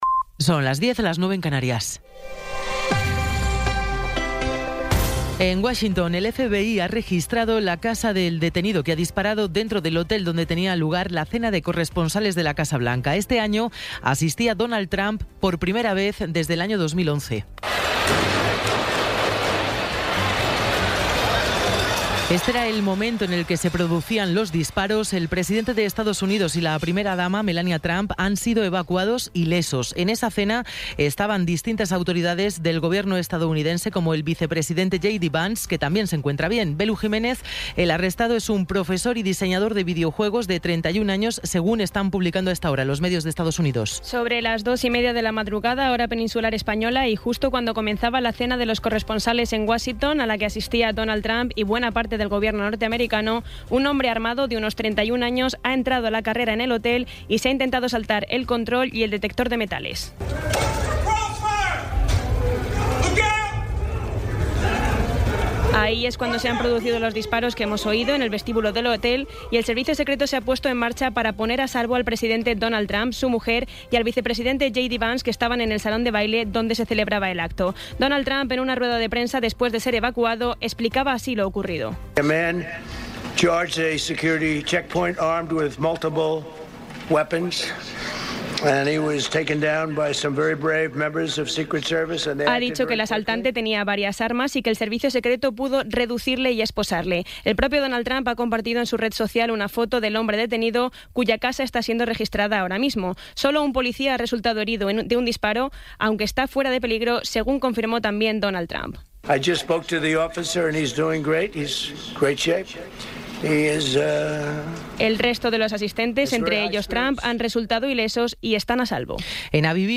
Resumen informativo con las noticias más destacadas del 26 de abril de 2026 a las diez de la mañana.